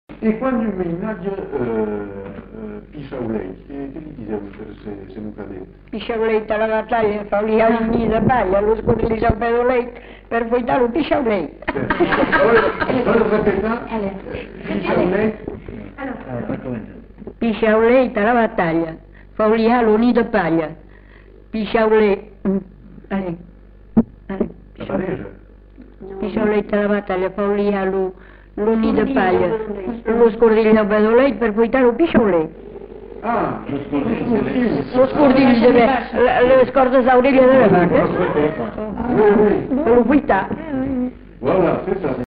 Lieu : Villandraut
Genre : forme brève
Type de voix : voix de femme
Production du son : récité
Classification : formulette enfantine